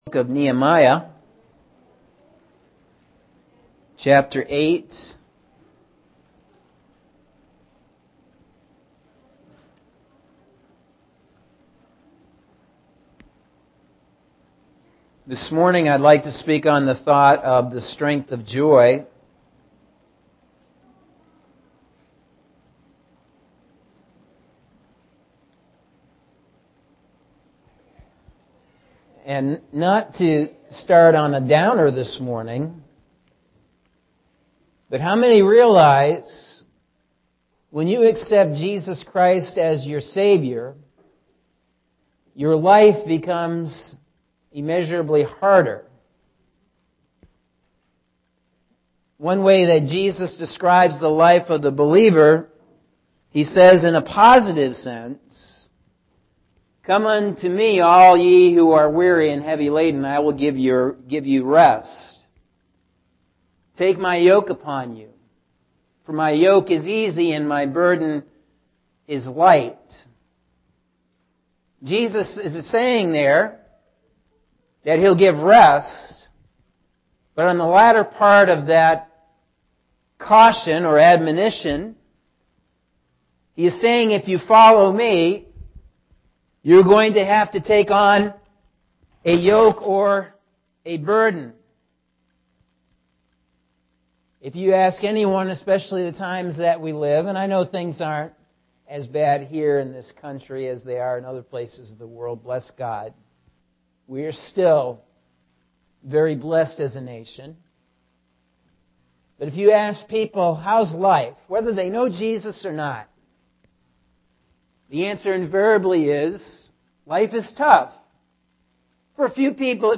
Sunday September 22nd – AM Sermon – Norwich Assembly of God